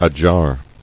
[a·jar]